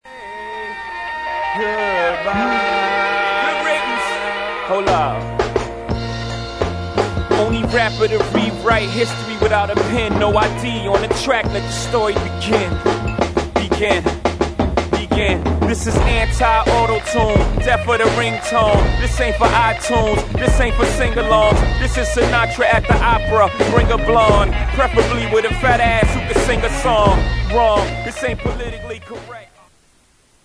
• Hip-Hop Ringtones